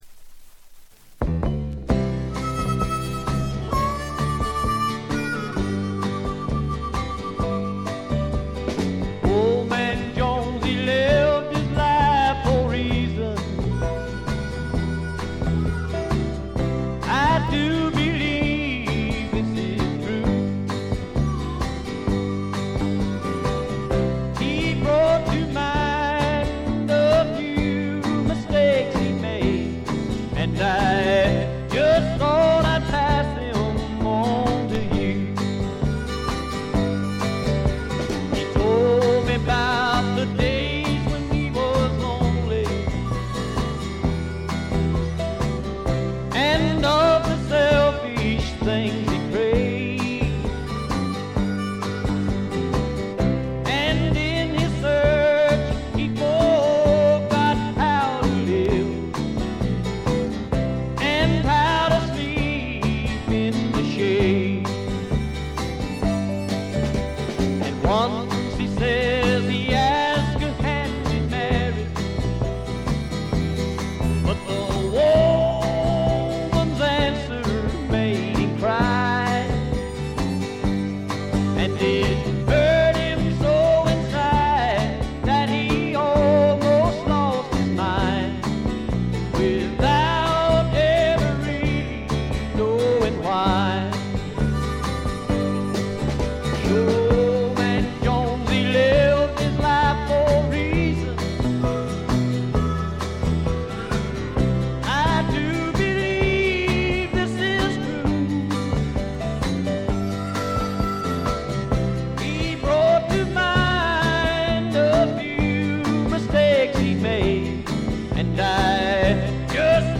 ごくわずかなノイズ感のみ。
謎のシンガー・ソングライター好盤です。
時代を反映してか、ほのかに香る土の匂いがとてもいい感じですね。
試聴曲は現品からの取り込み音源です。
Engineered At - Gold Star Studios